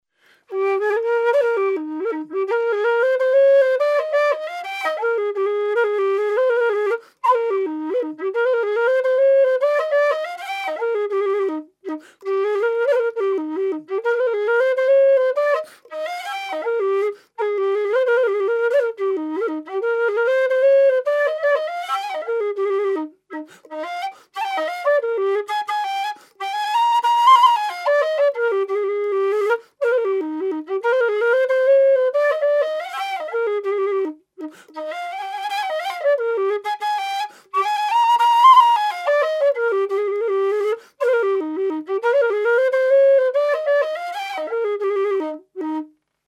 low Eb whistle - 200 GBP
made out of thin-walled aluminium tubing with 23mm bore
LowEb-Delahuntys_Hornpipe.mp3